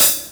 BR Hat Open.WAV